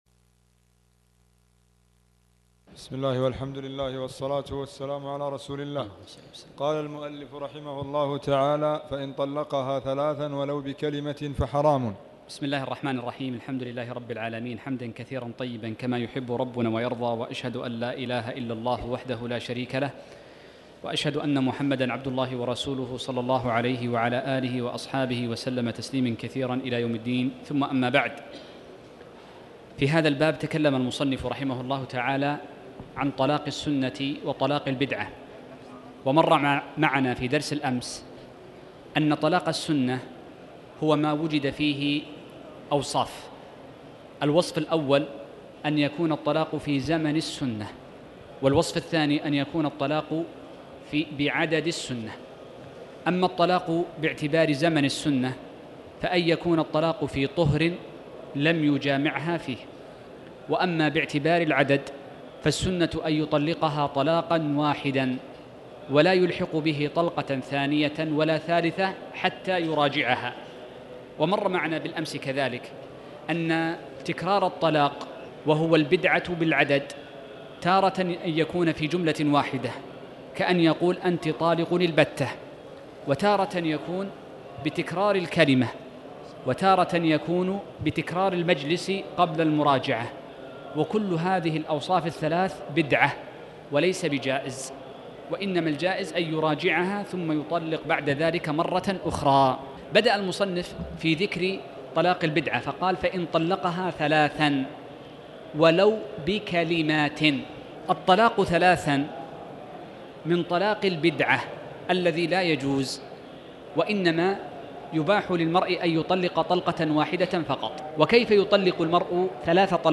تاريخ النشر ١٠ جمادى الأولى ١٤٣٩ هـ المكان: المسجد الحرام الشيخ